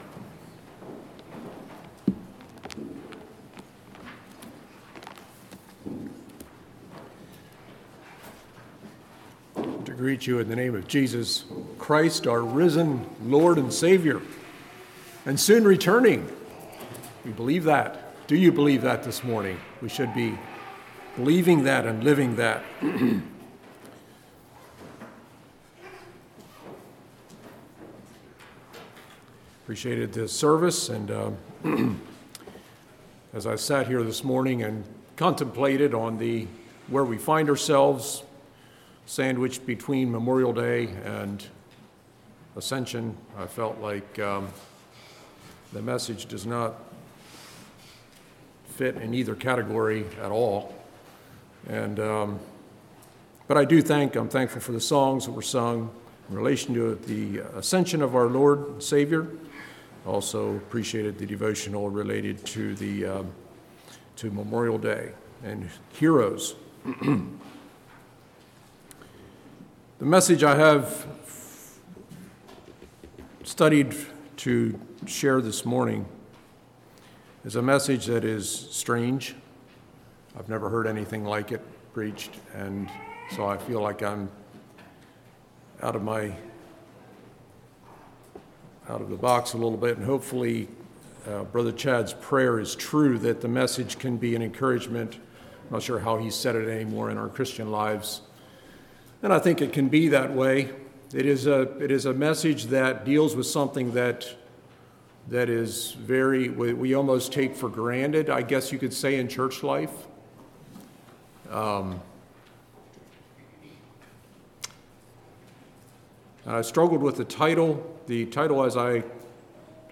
40:43 Summary: Teaching on the lot, and how it works. Why we use it in our ordinations.